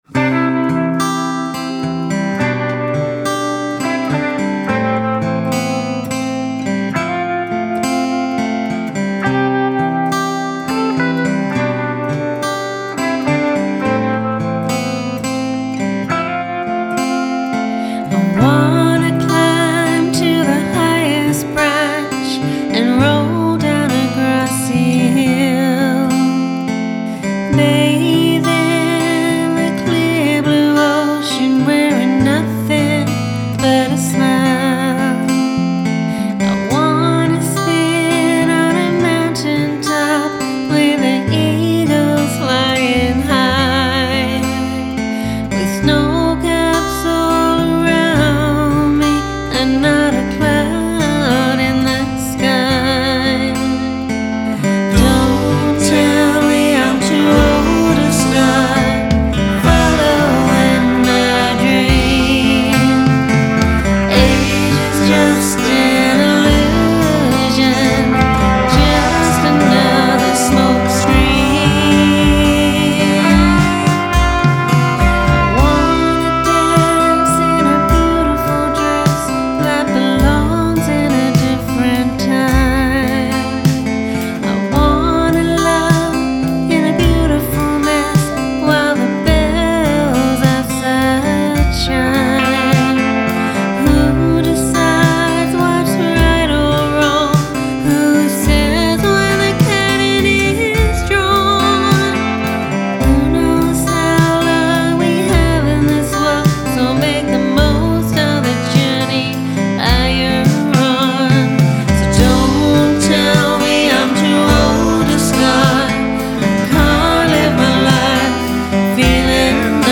Country duo